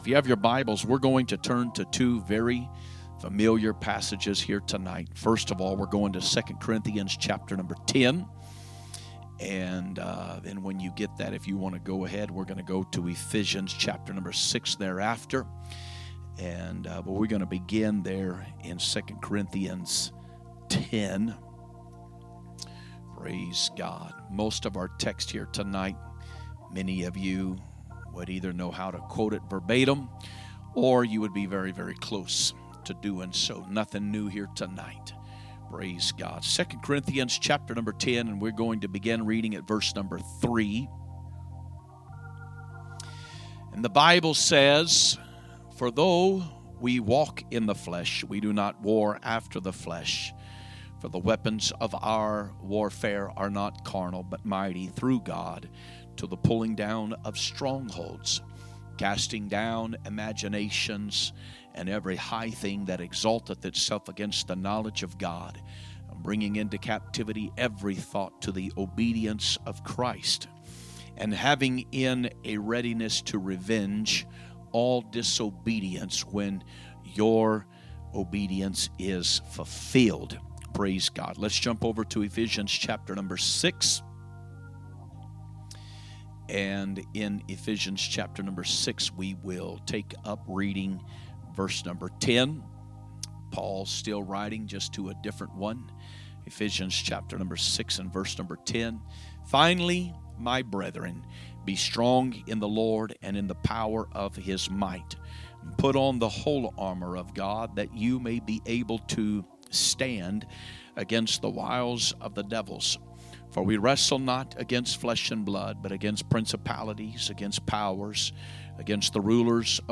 A message from the series "2025 Preaching." Pt. 2